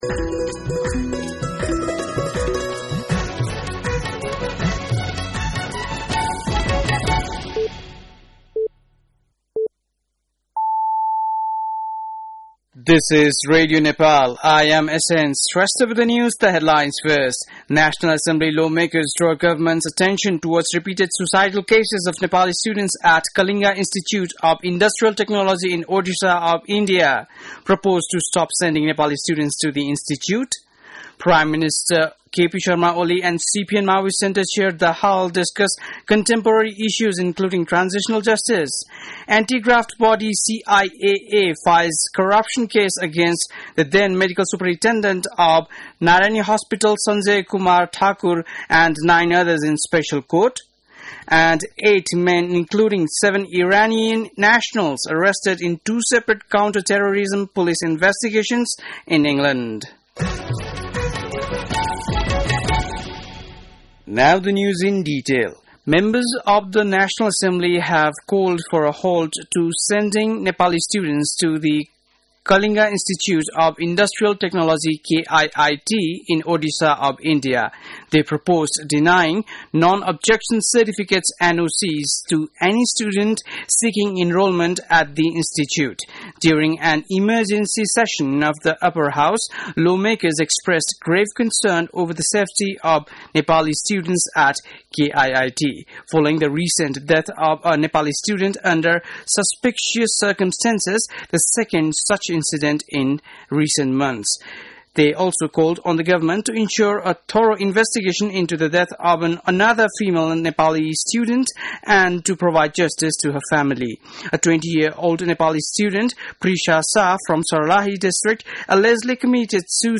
बेलुकी ८ बजेको अङ्ग्रेजी समाचार : २१ वैशाख , २०८२
8.-pm-english-news.mp3